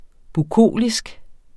Udtale [ buˈkoˀlisg ]